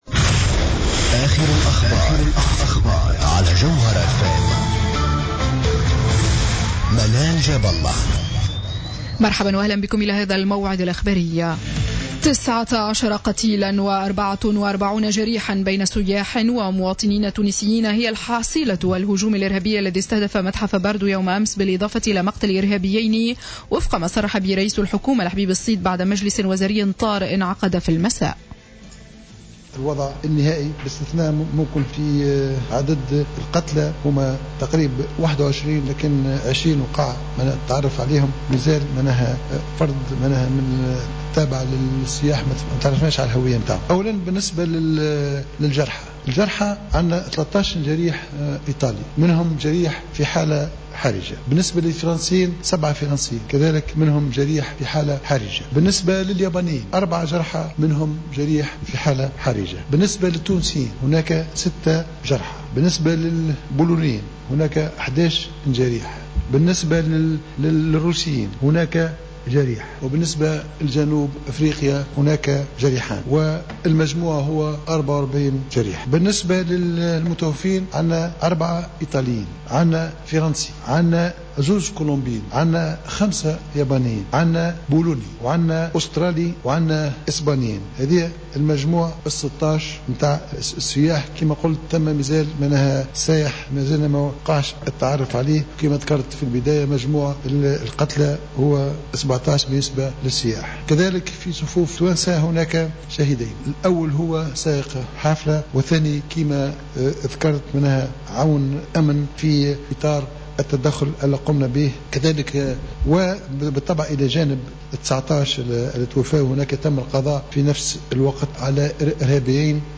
نشرة أخبار منتصف الليل ليوم الخميس 19 مارس 2015